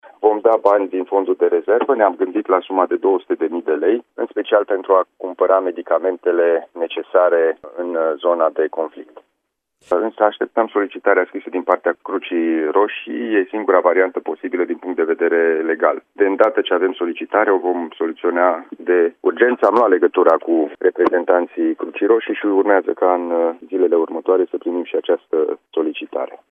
Banii provin din fondul de rezervă și sunt destinați, în special, pentru medicamente, a anunțat, la Radio Timișoara, președintele CJ Timiș, Alin Nica.